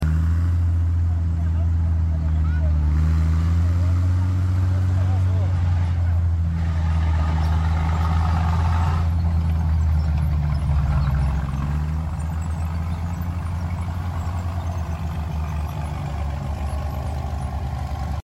The only running Nashorn SdkFz 164